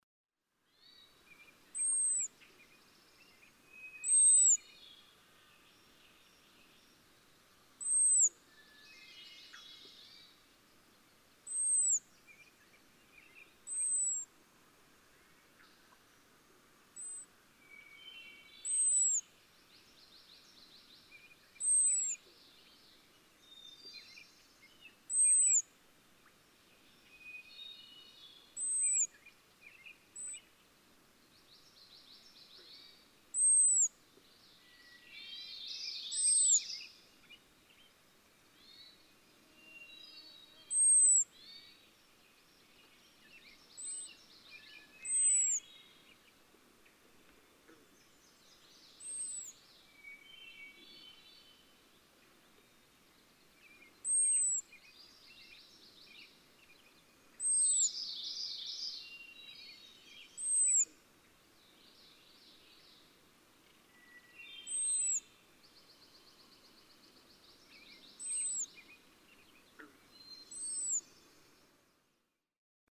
♫21. Call: the "hawk alarm," high, thin, seemingly ventriloquial.
Caribou, Maine.
021_American_Robin.mp3